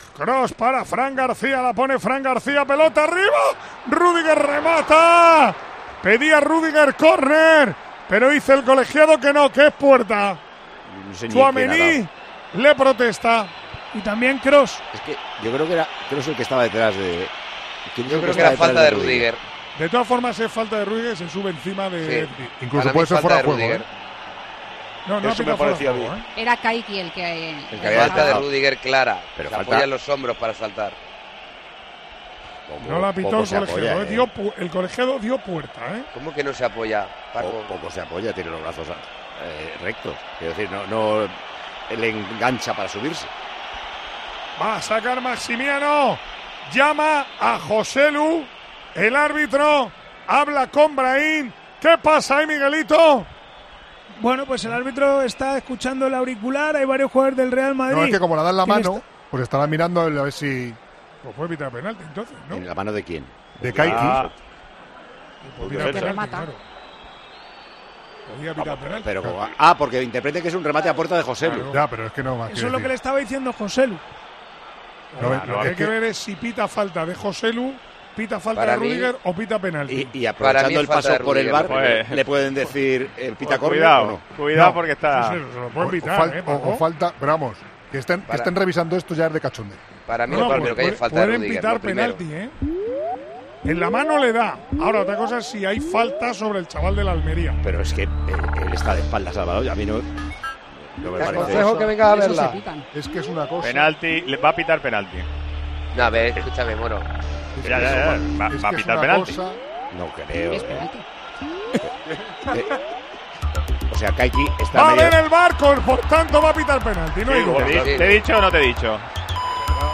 Así hemos vivido en Tiempo de Juego este polémico penalti.